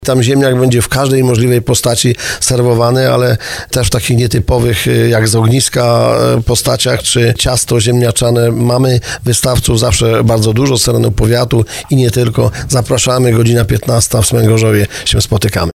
Burmistrz Dąbrowy Tarnowskiej Krzysztof Kaczmarski podkreśla, że tegoroczna edycja zapowiada się wyjątkowo smakowicie.